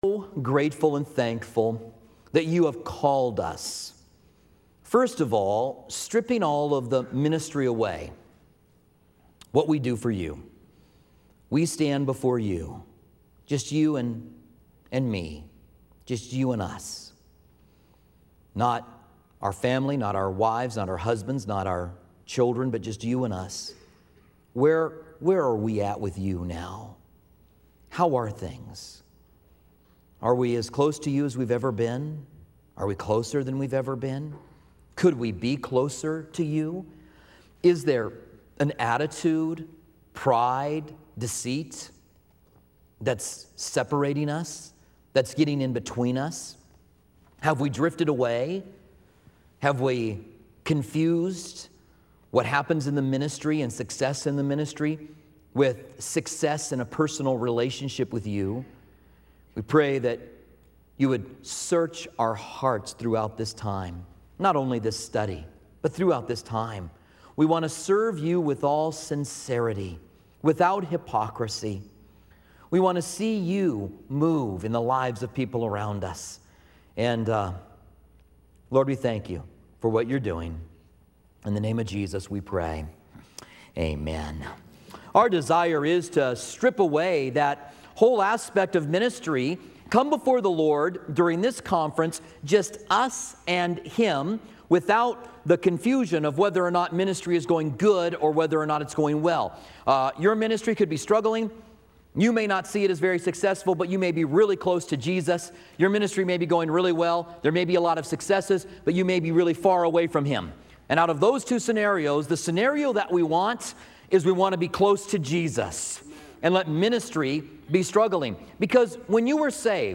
at the 2012 SW Pastors and Leaders Conference